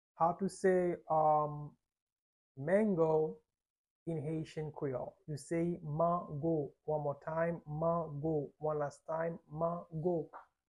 How to say "Mango" in Haitian Creole - "Mango" pronunciation by a native Haitian Teacher
“Mango” Pronunciation in Haitian Creole by a native Haitian can be heard in the audio here or in the video below:
How-to-say-Mango-in-Haitian-Creole-Mango-pronunciation-by-a-native-Haitian-Teacher.mp3